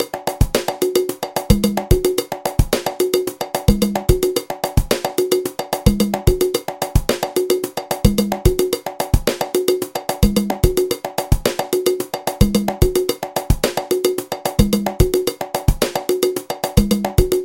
Ensemble de la rythmique de la Timba 2